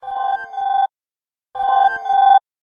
rt_chrome_alarm.ogg